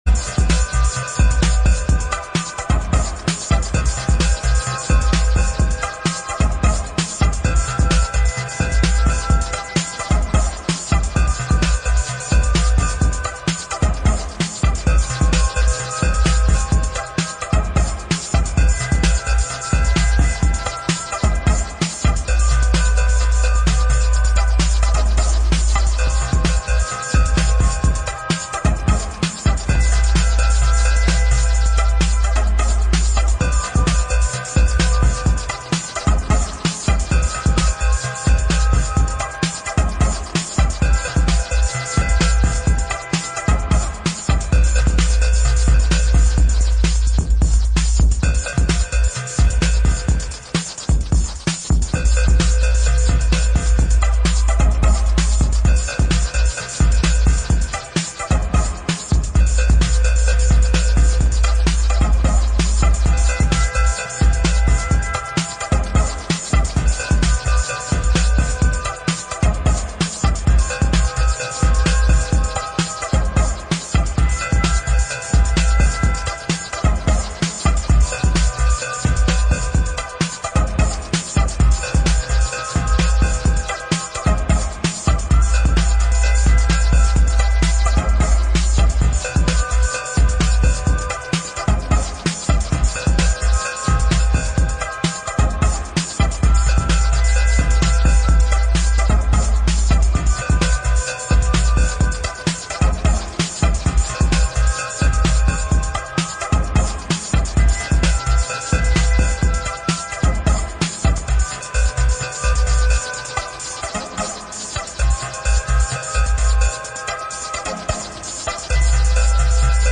This recently discovered DAT-tape
Electro Techno Detroit